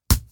lemon-hit.mp3